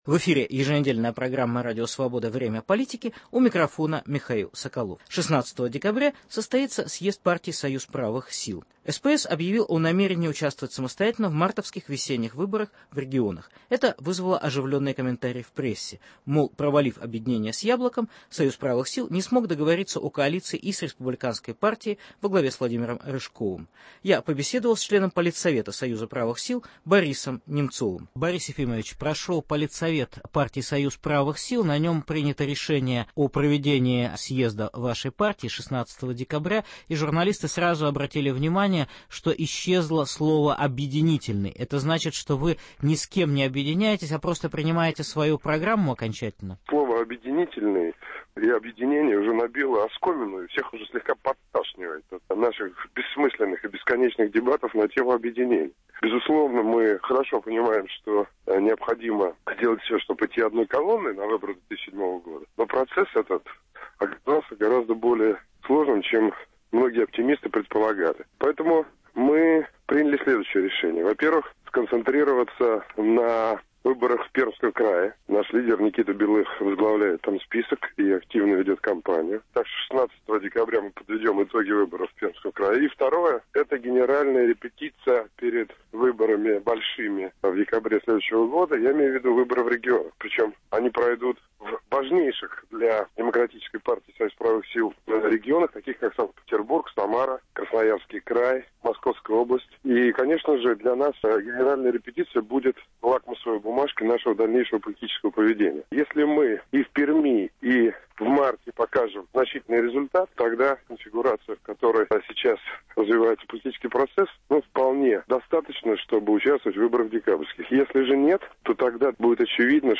СПС перед декабрьским съездом. Интервью Бориса Немцова.